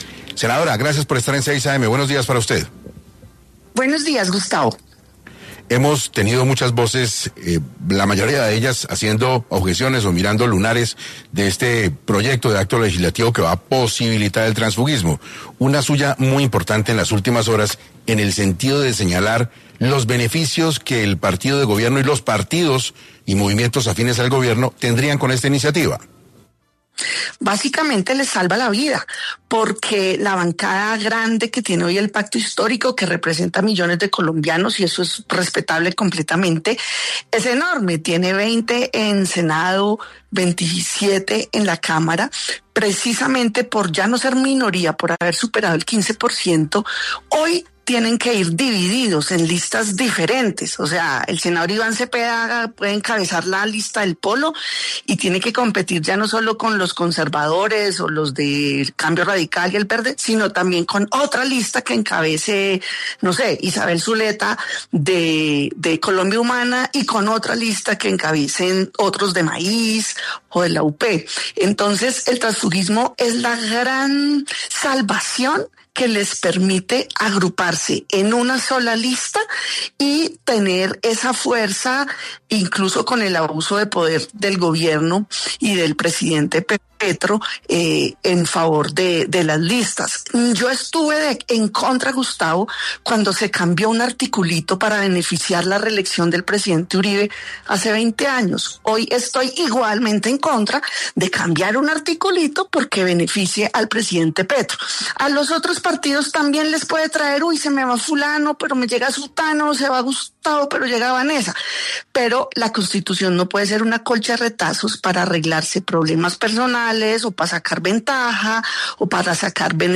En Caracol Radio estuvo la congresista para explicar el proyecto de ley que regula el transfuguismo político.